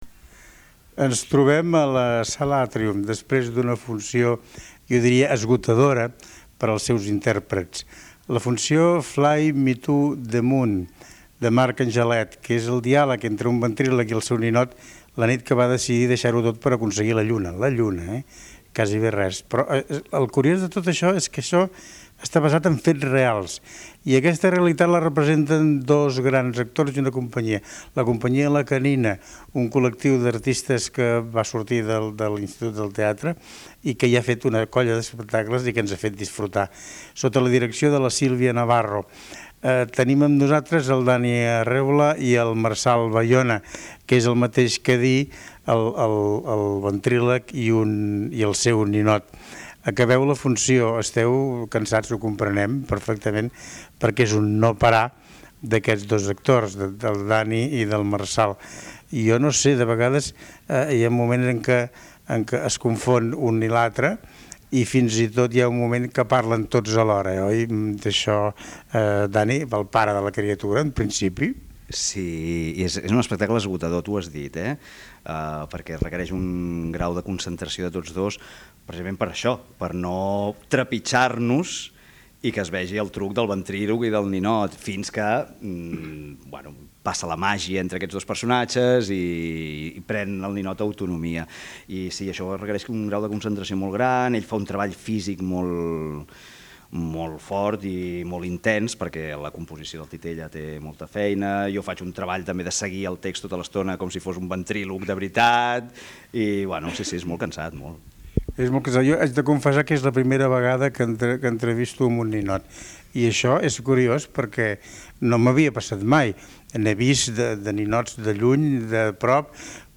Podeu trobar l'entrevista